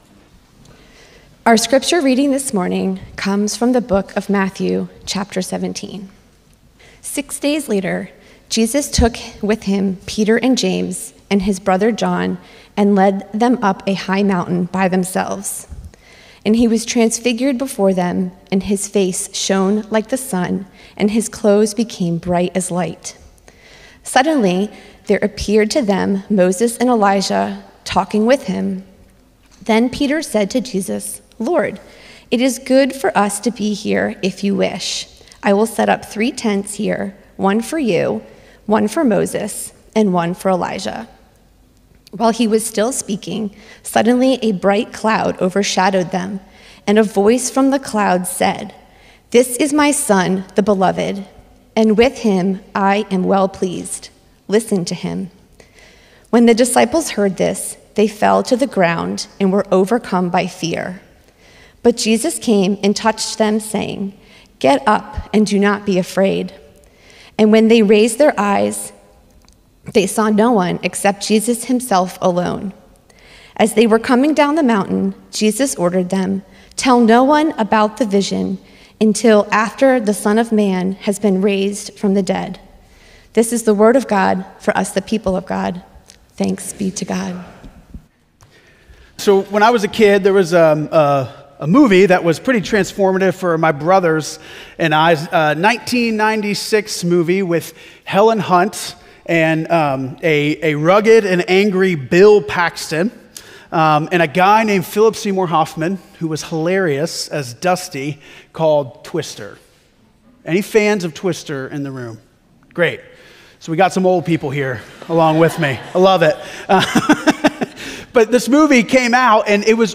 First Cary UMC's First on Chatham Sermon &ndash